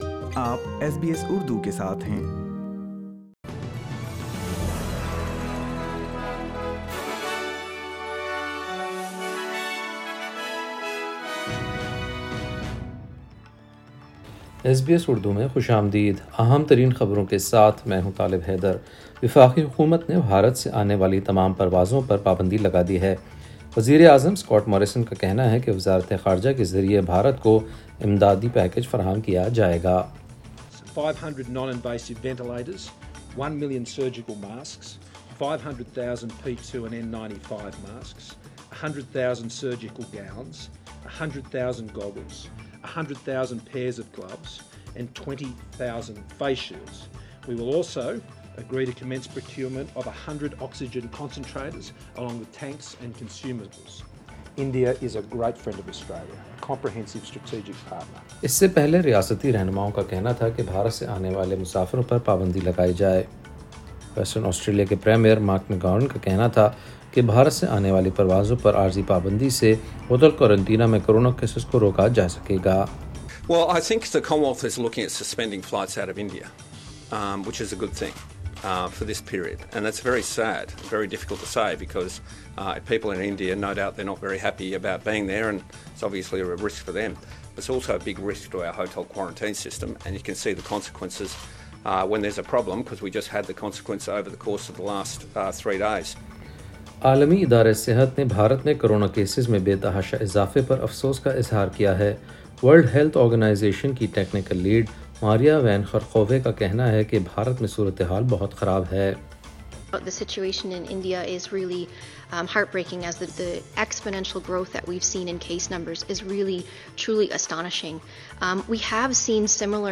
In this bulletin.... Australia suspends all flights from India until at least mid-May, the W-H-O has redeployed 2600 staff members from other programs in India to help support the effort to fight the disease.